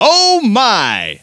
Ohmy.wav